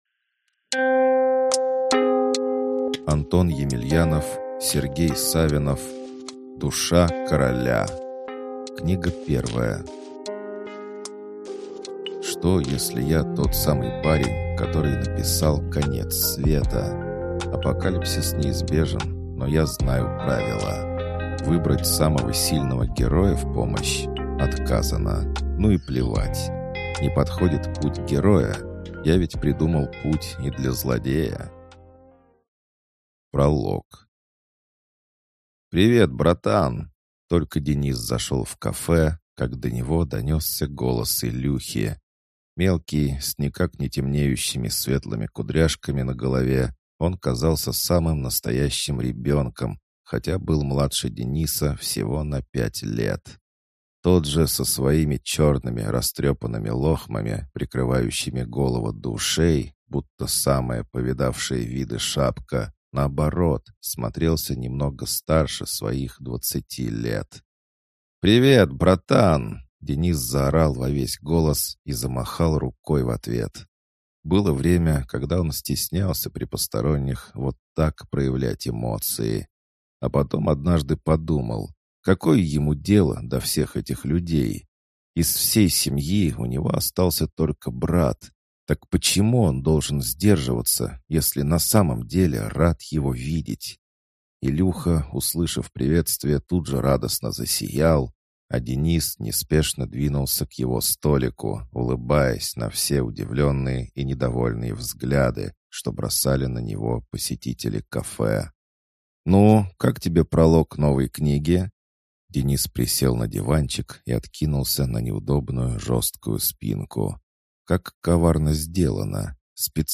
Аудиокнига Душа короля. Книга 1 | Библиотека аудиокниг